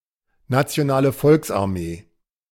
The National People's Army (German: Nationale Volksarmee, pronounced [natsi̯oˈnaːlə ˈfɔlksʔaʁˌmeː]
De-Nationale_Volksarmee.ogg.mp3